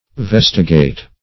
Vestigate - definition of Vestigate - synonyms, pronunciation, spelling from Free Dictionary
Search Result for " vestigate" : The Collaborative International Dictionary of English v.0.48: Vestigate \Ves"ti*gate\, v. t. [L. vestigatus, p. p. of vestigare.
vestigate.mp3